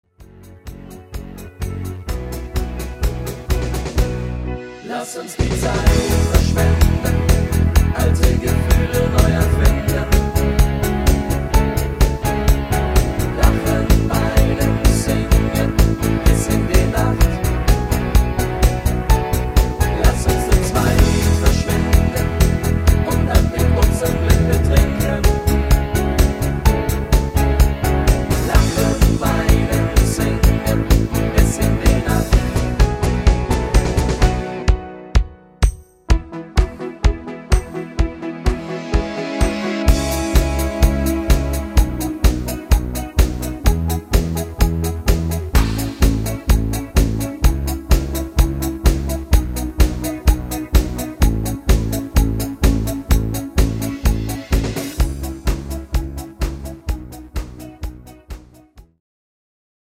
Rhythmus  Discofox